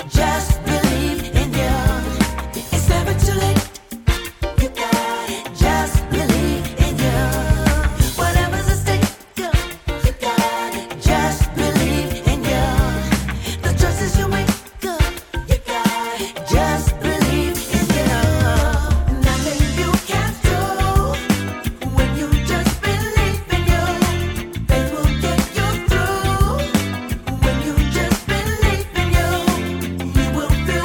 chant...